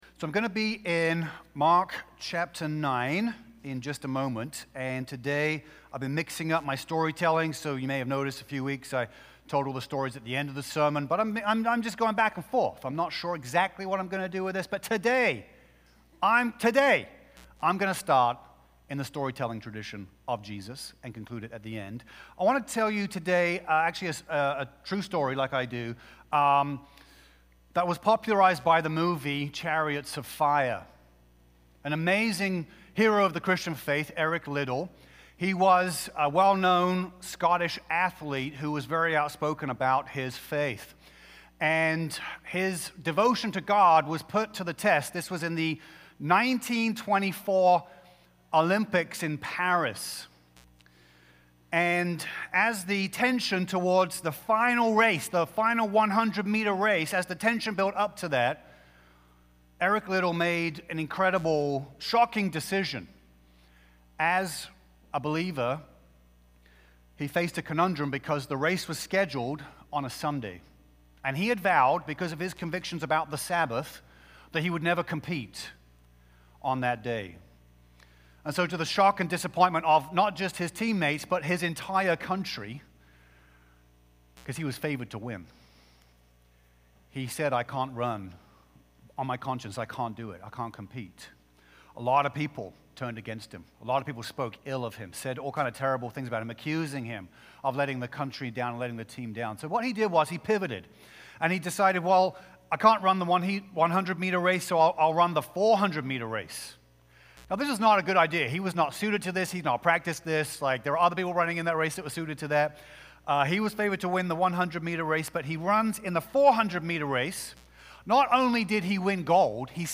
March-1-2026-Full-Sermon.mp3